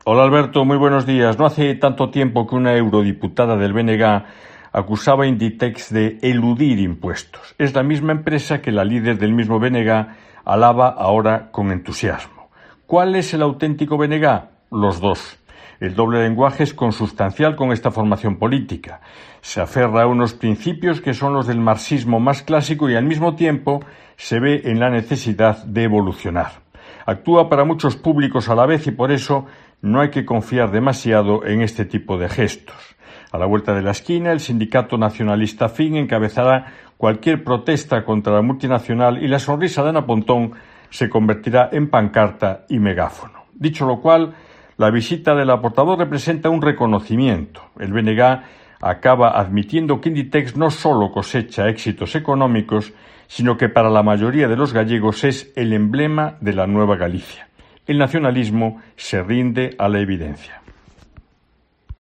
En su comentario en Cope Galicia